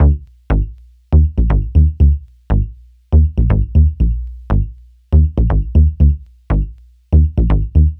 TSNRG2 Bassline 028.wav